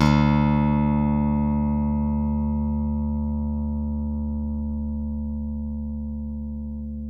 ALEM PICK D2.wav